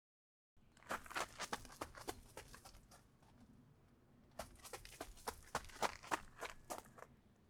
砂利道を走る – OtoPocke
砂利道を走る
砂利道_走る長め.wav